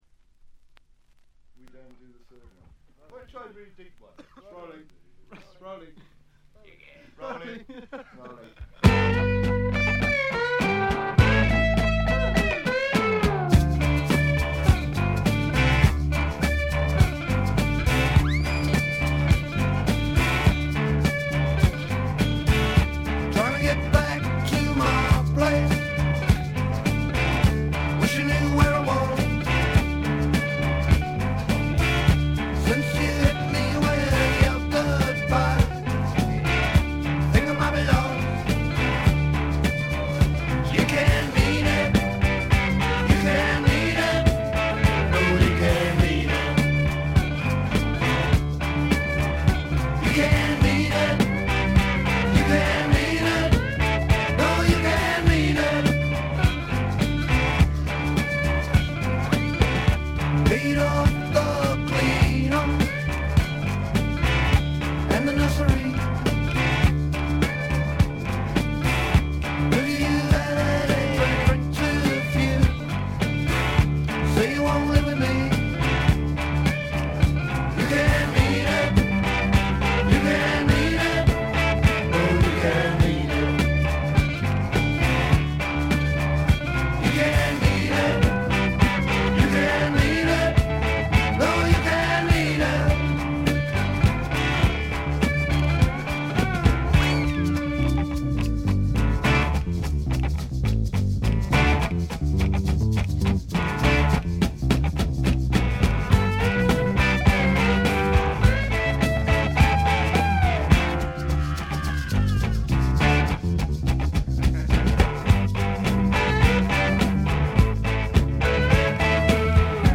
軽微なチリプチ少々、プツ音少々。
試聴曲は現品からの取り込み音源です。
Recorded At - Riverside Studios, London